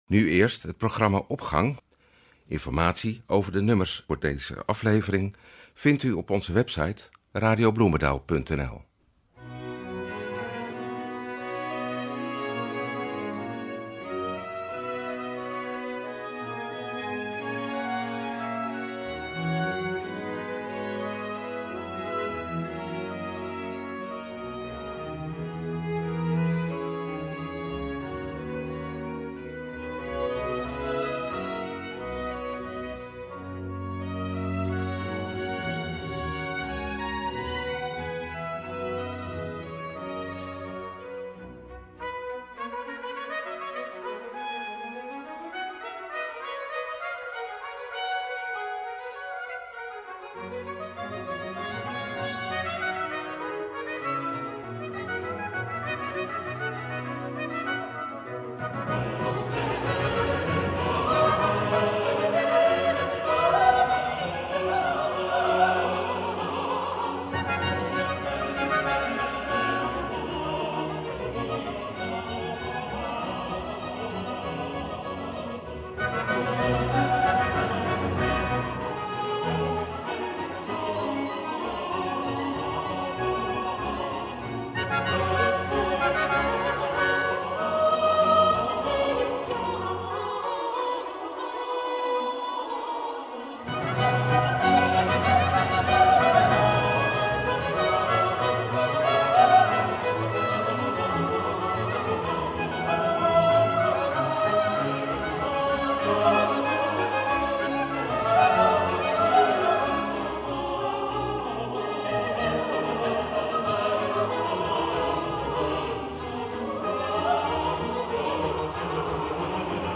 maar twee hedendaagse koorcomposities.
Cantate